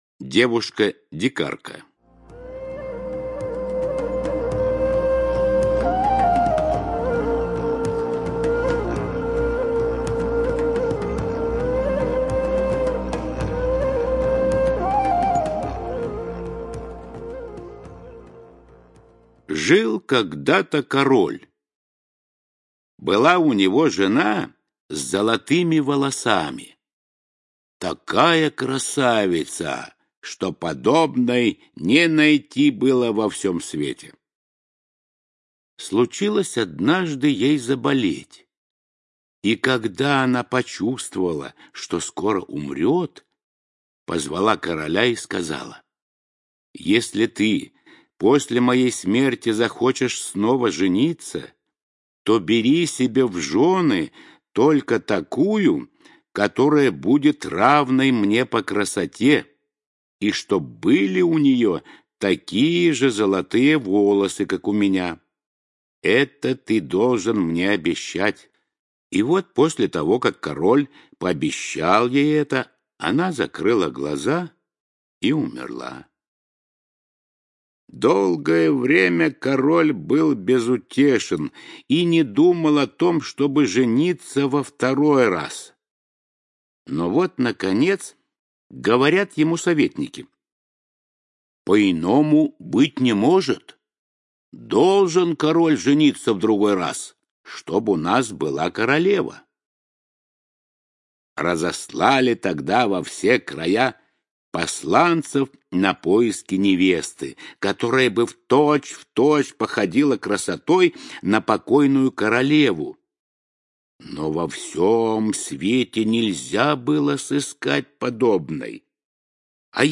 Девушка-дикарка - аудиосказка братьев Гримм.